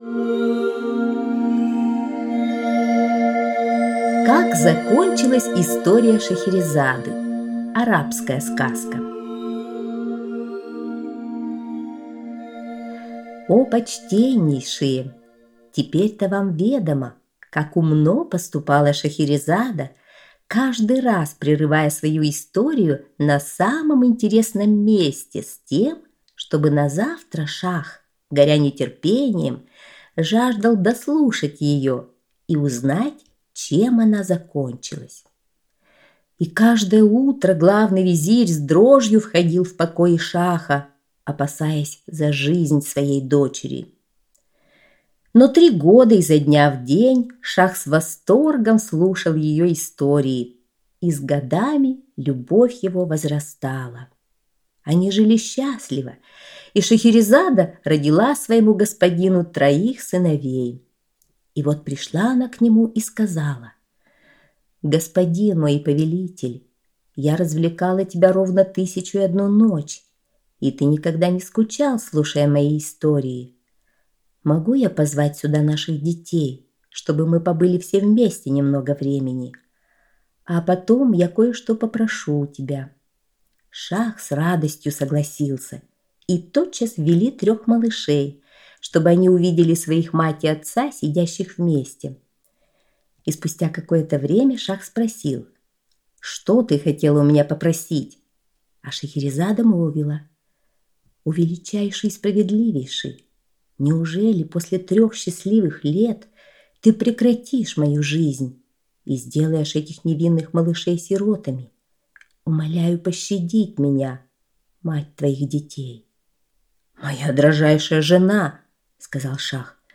Шахерезада: Как закончилась история Шахерезады – арабская аудиосказка